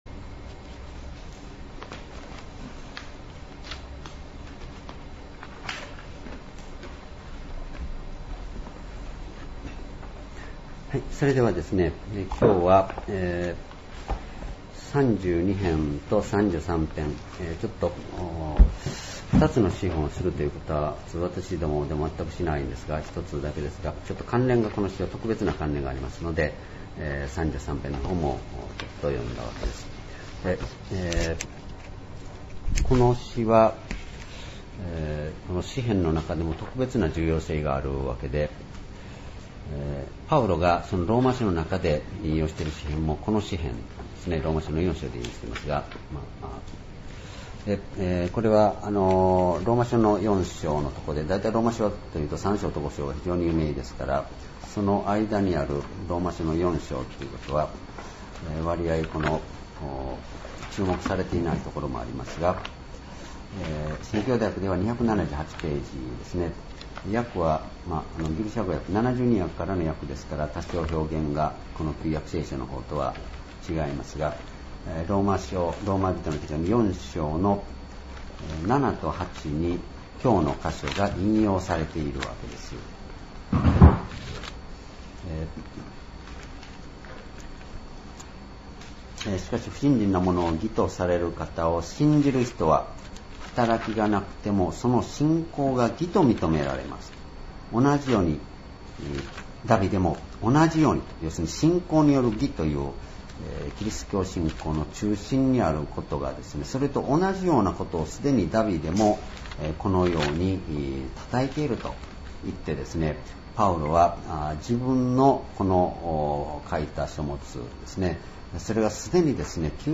主日礼拝日時 1月12日 聖書講話箇所 詩編３２-33篇 「最も大いなる幸いとしての罪の赦し」 ※視聴できない場合は をクリックしてください。